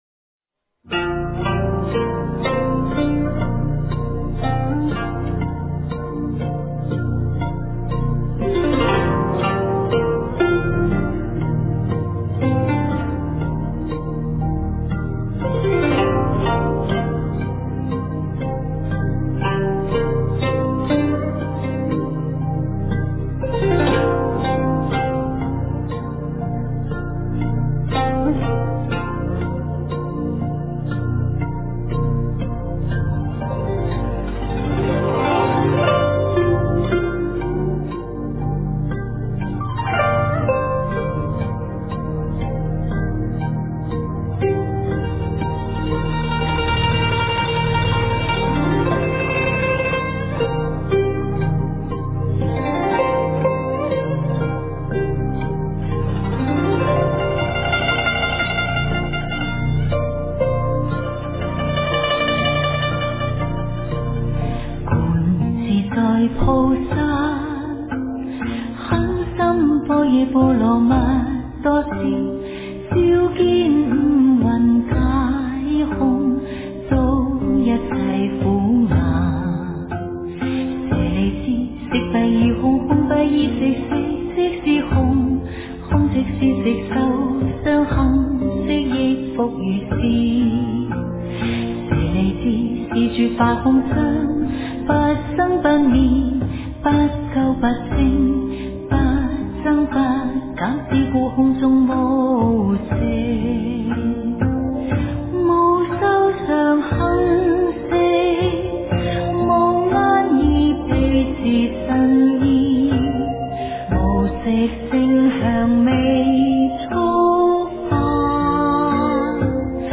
心经 诵经 心经--粤海梵音 点我： 标签: 佛音 诵经 佛教音乐 返回列表 上一篇： 大悲咒 下一篇： 般若波罗密多心经 相关文章 阿弥陀佛--心定和尚 阿弥陀佛--心定和尚...